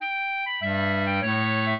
clarinet
minuet10-2.wav